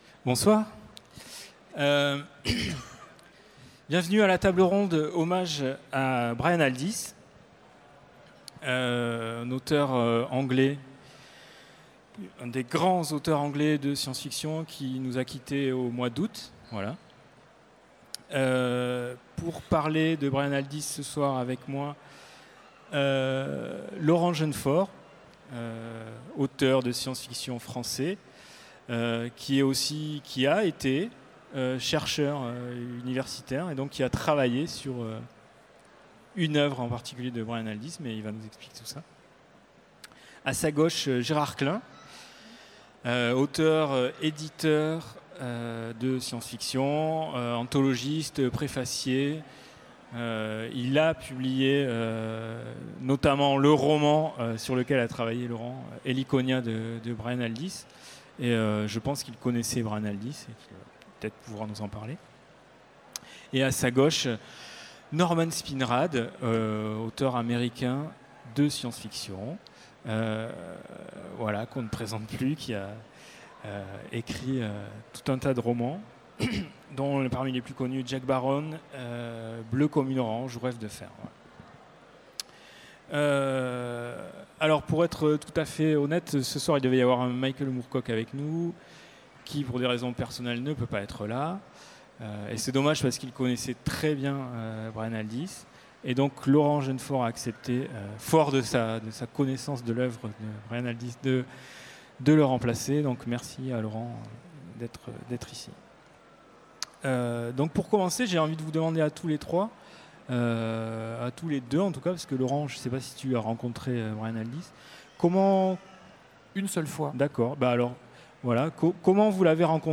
Utopiales 2017 : Conférence Hommage à Brian Aldiss